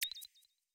song-ping-variation-1.wav